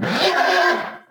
hurt2.ogg